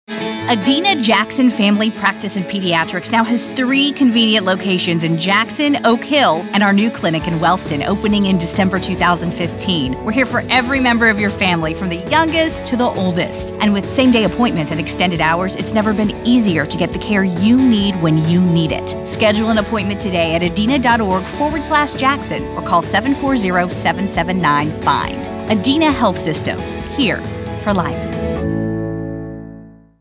Voice Over Work – 30-second Sample
Voice-Over-Work-30-second-sample.mp3